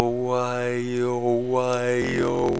Vowels signal result (AEIOU) singed
aeiou.wav